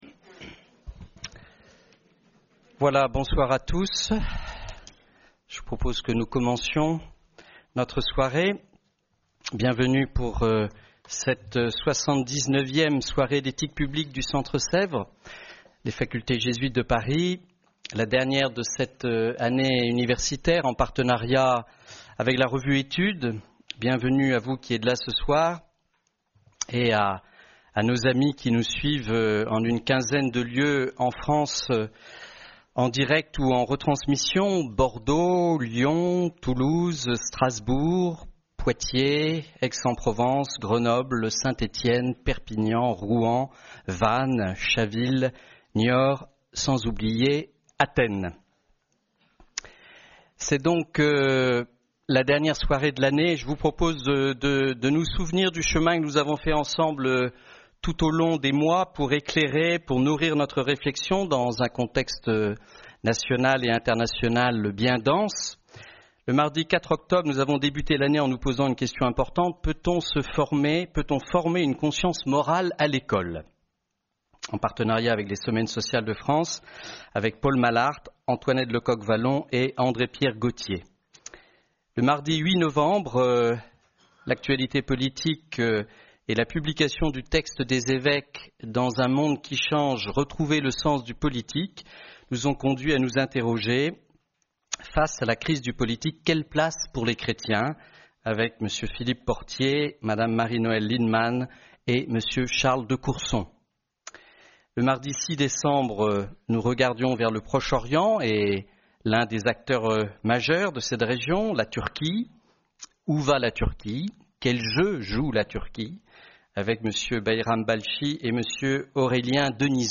Soirée Mardi d’Ethique publique en partenariat avec les Semaines Sociales de France, LA CROIX, RCF et Études.